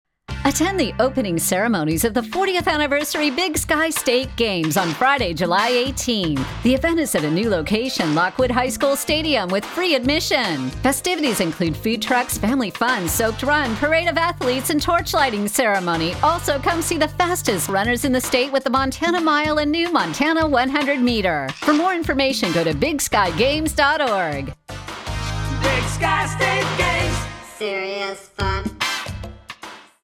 Big-Sky-State-Games-2025-BSSG-Opening-Ceremony-RADIO.mp3